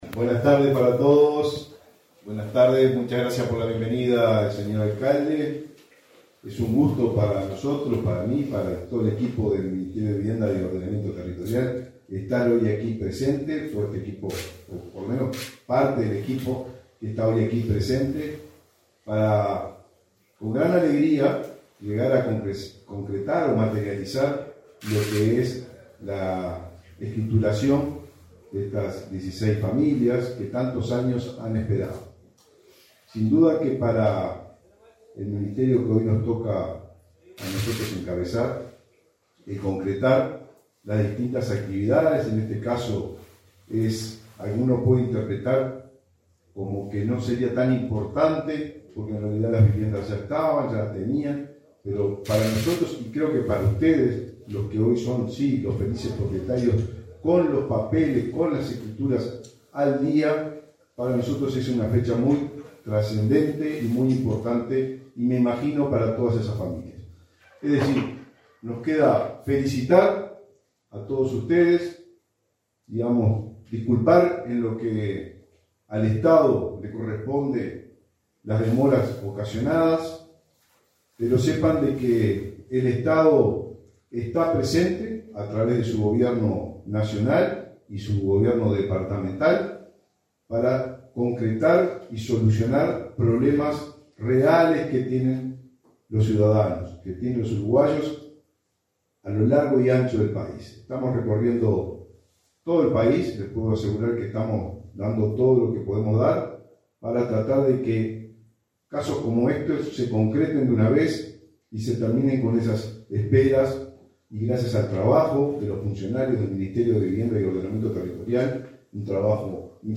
Palabras del ministro de Vivienda, Raúl Lozano
Palabras del ministro de Vivienda, Raúl Lozano 19/09/2023 Compartir Facebook X Copiar enlace WhatsApp LinkedIn El titula del Ministerio de Vivienda y Ordenamiento Territorial (MVOT), Raúl Lozano, participó, este 19 de setiembre, en la entrega de títulos de propiedad a 16 familias de José Pedro Varela, en el departamento de Lavalleja.